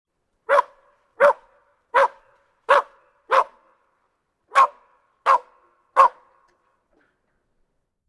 Dog Bark Sound Effect: Unblocked Meme Soundboard
Play the iconic Dog Bark Sound Effect for your meme soundboard!